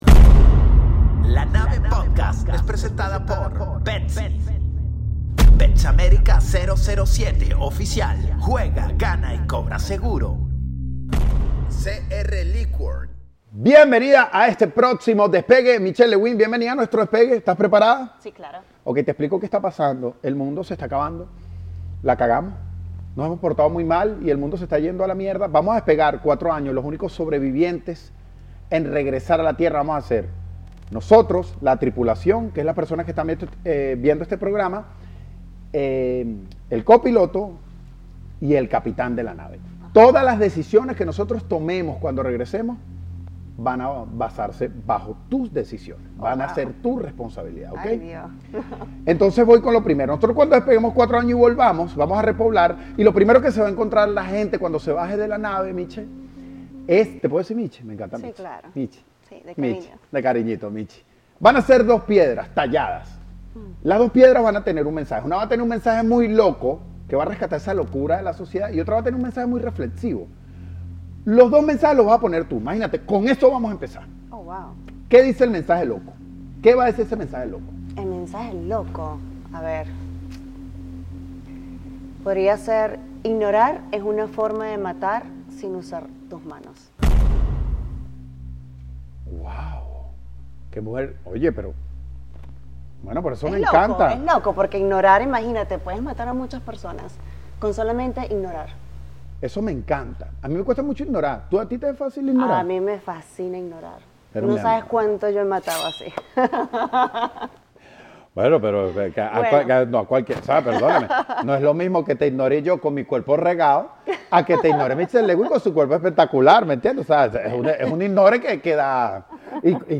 El co-piloto de este episodio es Michelle Lewin, la modelo y diva del fitness. La bodybuilder más codiciada se monta en La Nave y crea el androide más envidiable PERO CON UN INGREDIENTE ESPECIAL!